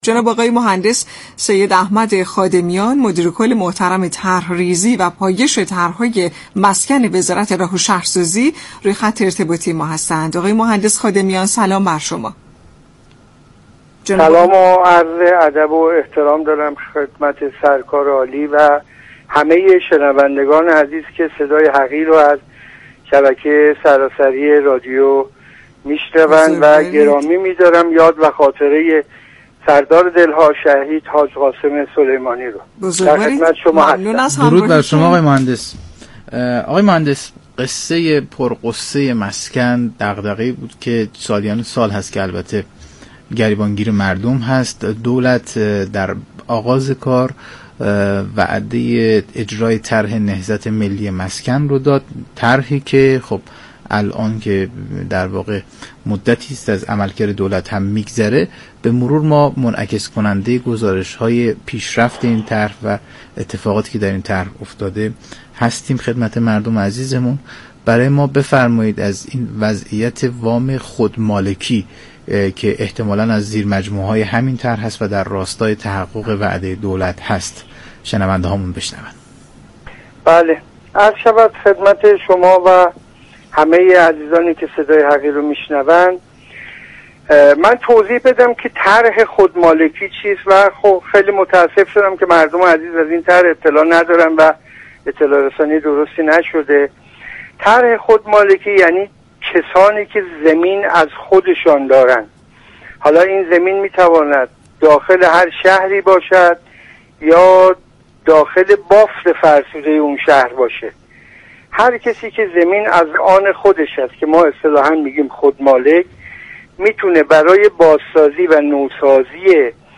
برنامه «نمودار» شنبه چهارشنبه هر هفته ساعت 10:20 از رادیو ایران پخش می شود.